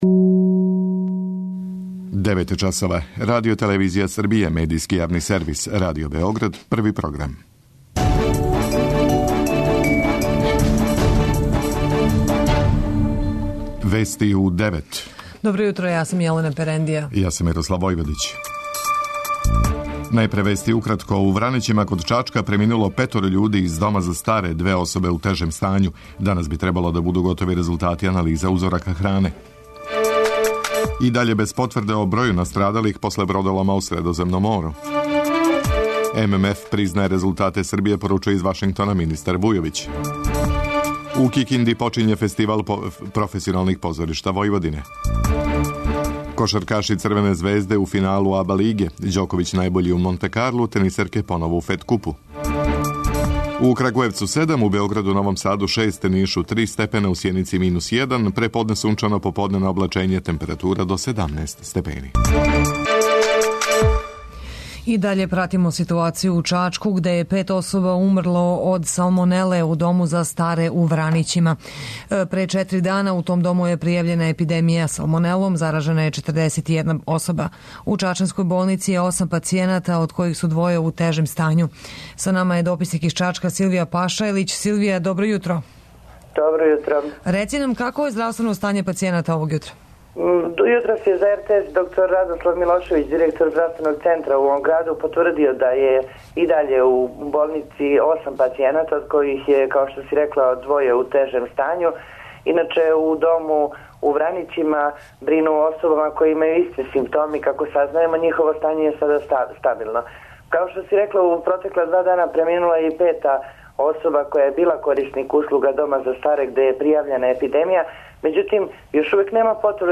преузми : 9.95 MB Вести у 9 Autor: разни аутори Преглед најважнијиx информација из земље из света.